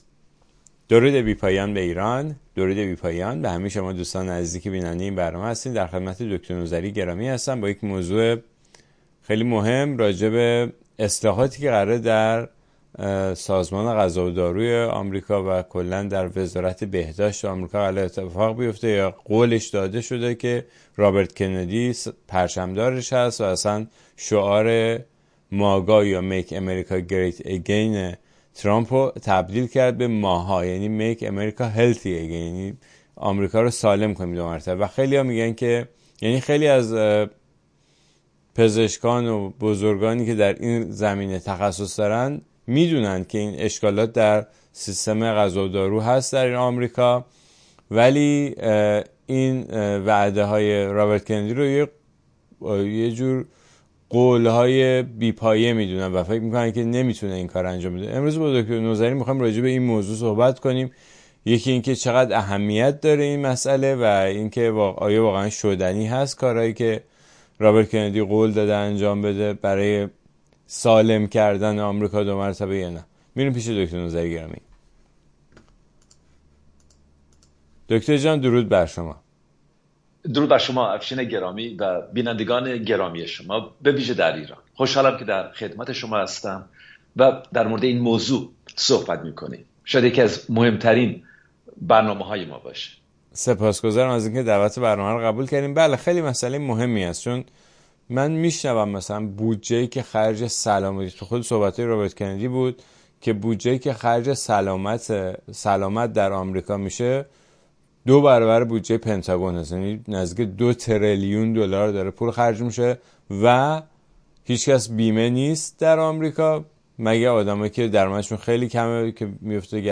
در گفتگوی پیش رو